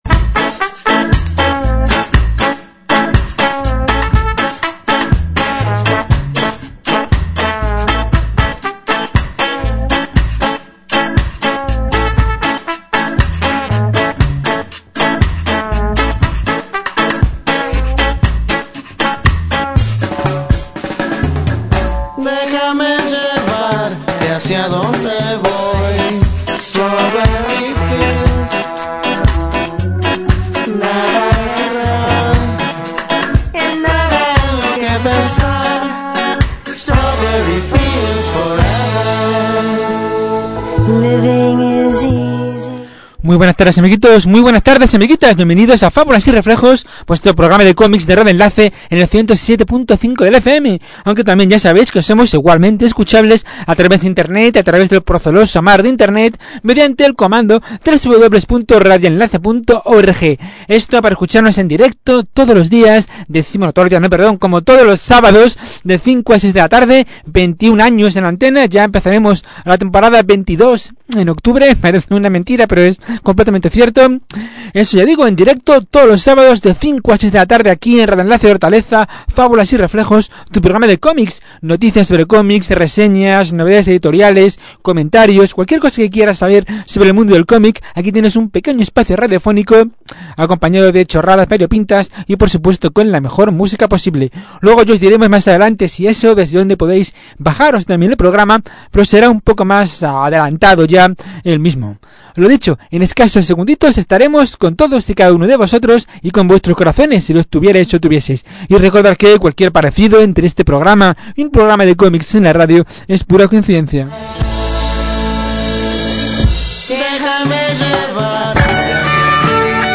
Es por ello que encontrarás un programa, repleto de títulos, humor, y todo siempre acompañado de la mejor música posible... Un buen "recopila" de los 60, 70 .